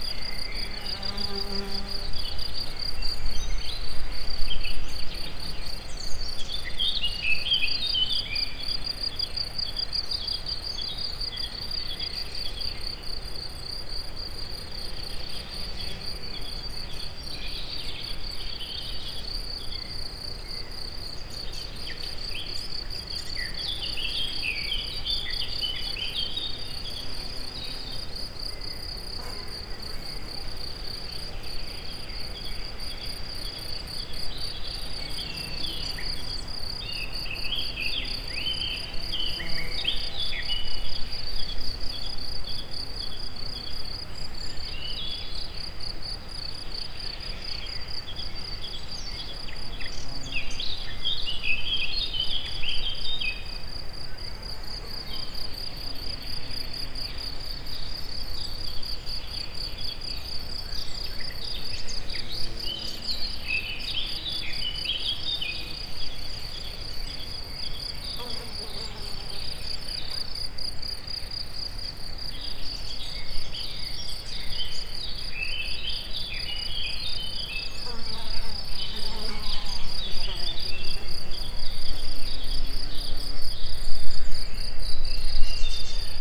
Directory Listing of /_MP3/allathangok/termeszetben/rovarok_premium/
halkes7_hangoskornyezet_egerturistahaz01.26.WAV